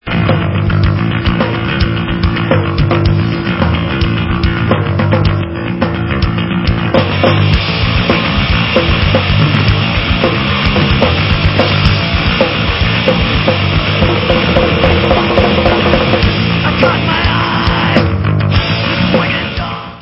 sledovat novinky v oddělení Rock/Punk